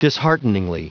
Prononciation du mot dishearteningly en anglais (fichier audio)
Prononciation du mot : dishearteningly